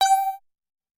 标签： FSharp5 MIDI音符-79 雅马哈-CS-30L 合成器 单票据 多重采样
声道立体声